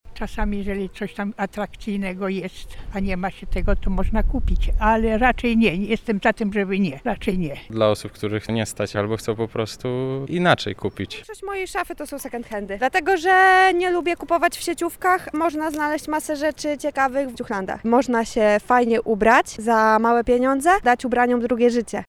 Nasza reporterka zapytała mieszkańców Lublina, czy kupują ubrania z drugiej ręki:
sonda